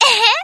comboburst.wav